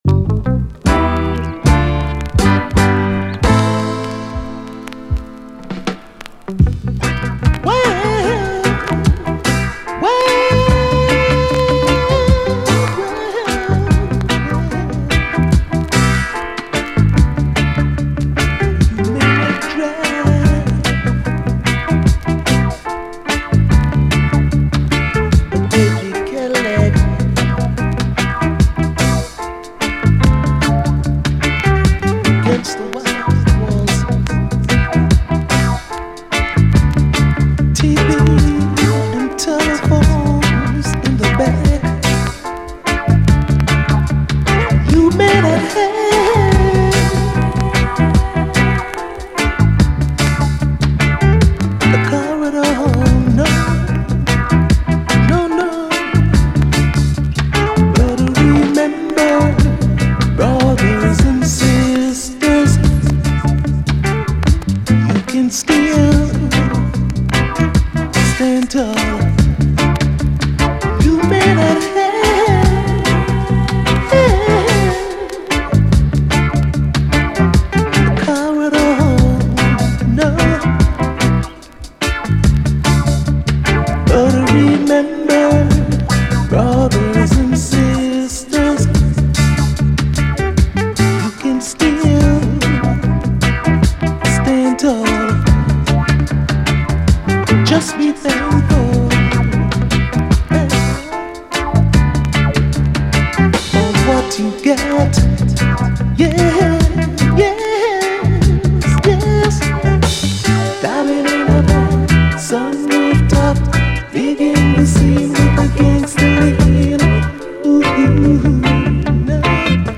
REGGAE, 7INCH